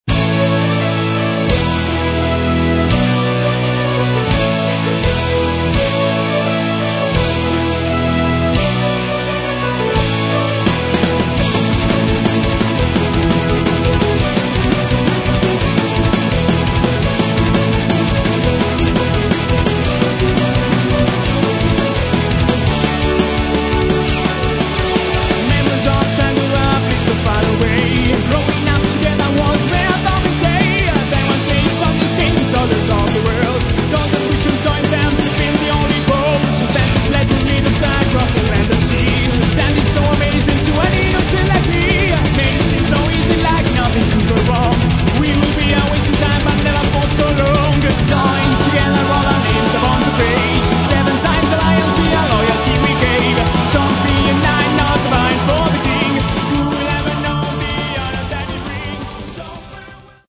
Metal
Постоянные «дуэли» гитар и клавиш.